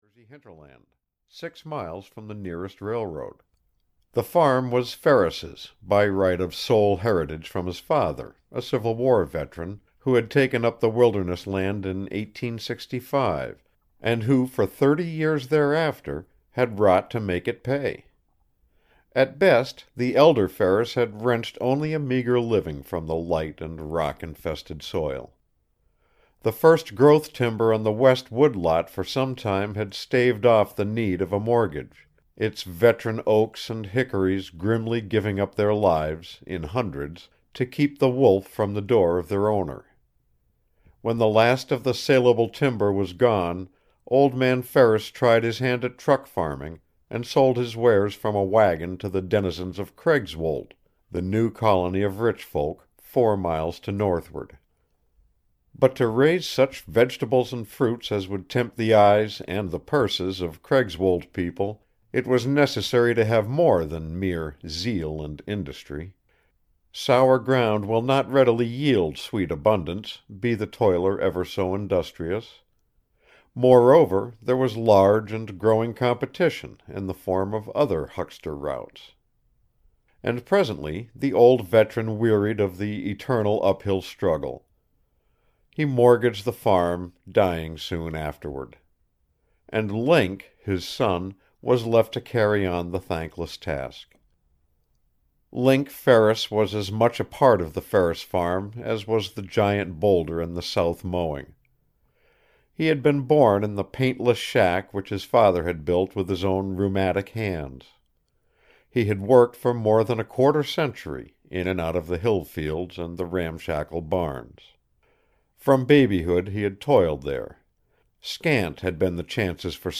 His Dog (EN) audiokniha
Ukázka z knihy